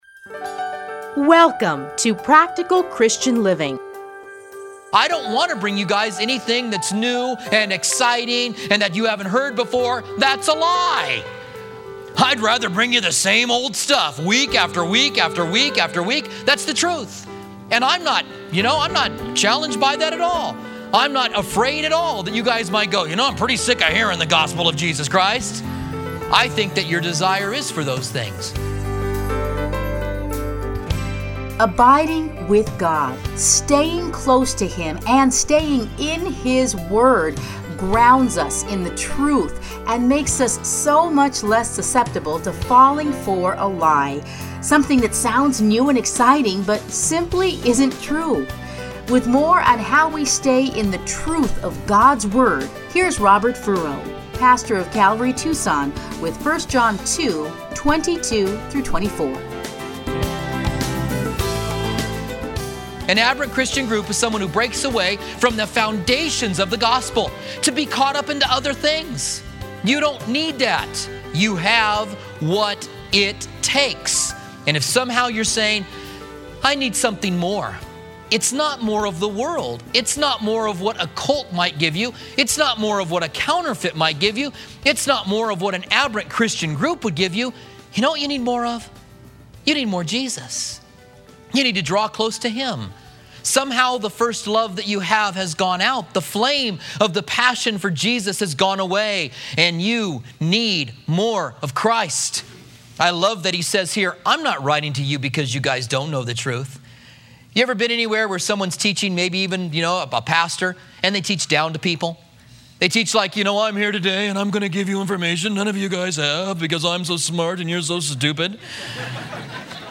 30-minute radio programs